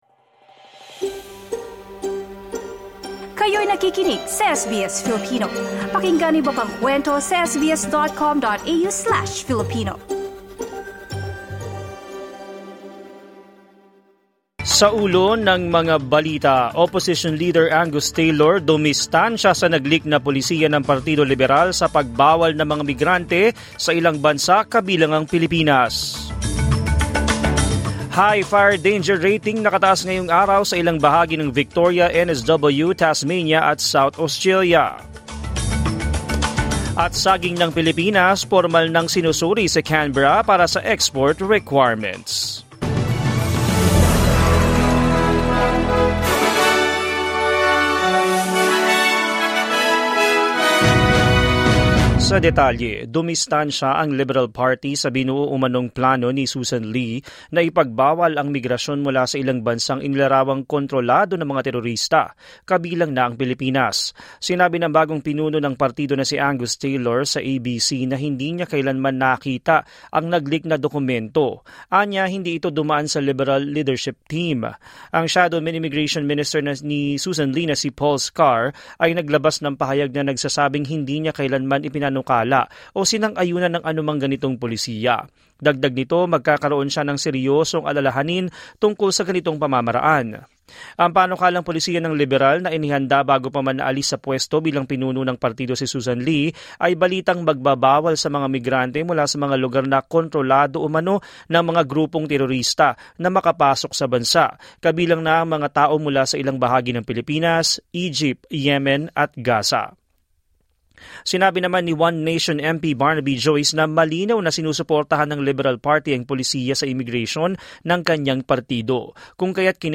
SBS News in Filipino, Tuesday 17 February 2026